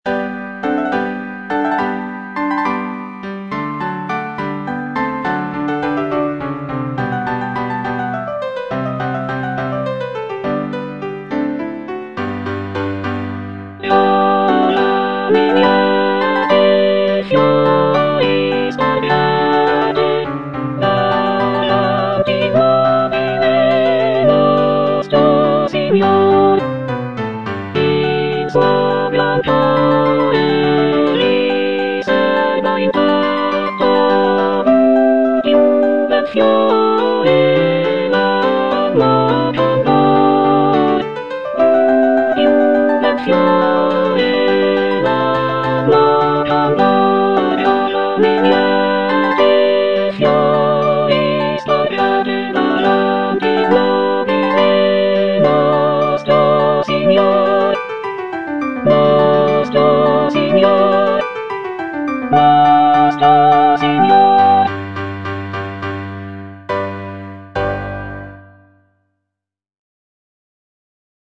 W.A. MOZART - CHOIRS FROM "LE NOZZE DI FIGARO" KV492 Giovani liete, fiori spargete - Soprano (Emphasised voice and other voices) Ads stop: